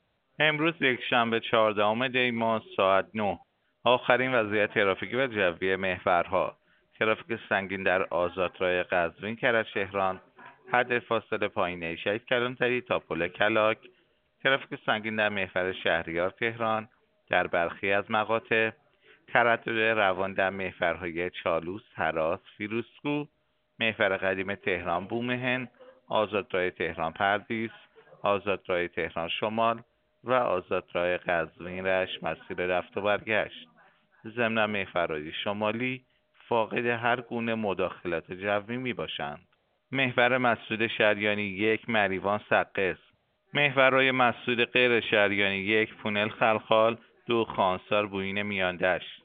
گزارش رادیو اینترنتی از آخرین وضعیت ترافیکی جاده‌ها ساعت ۹ چهاردهم دی؛